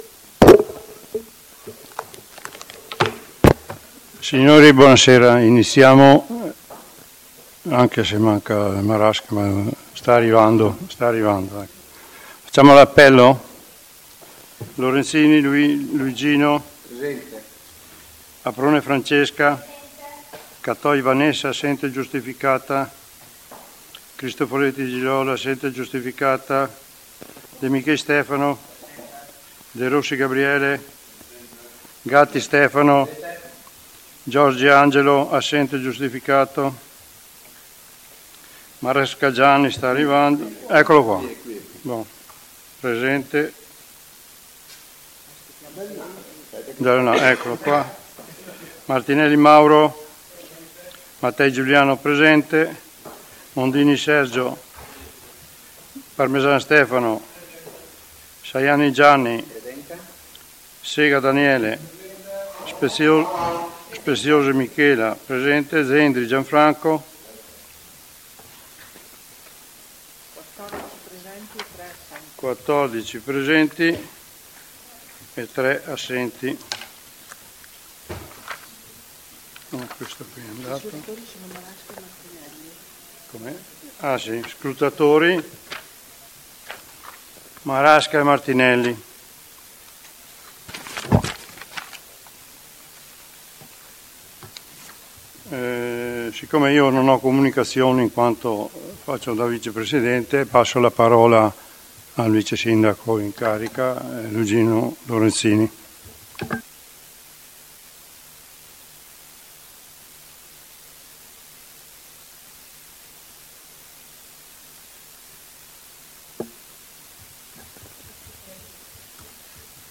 AudioSedutaConsiglio.mp3